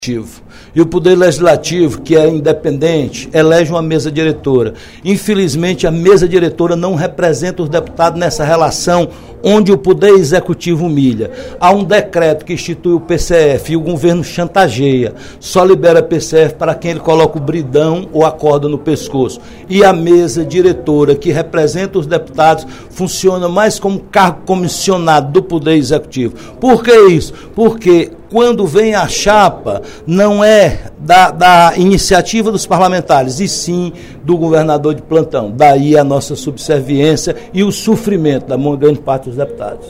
O deputado Roberto Mesquita (PSD) comentou, no primeiro expediente da sessão plenária desta sexta-feira (03/06), sobre a relação entre a Mesa Diretora da Assembleia Legislativa e os demais parlamentares da Casa.